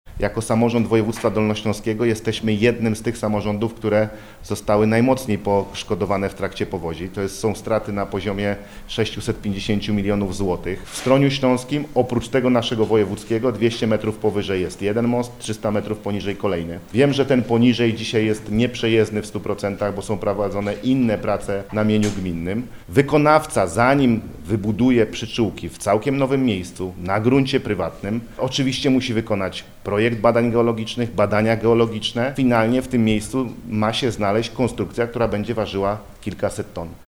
Mówi Paweł Gancarz, Marszałek Województwa Dolnośląskiego.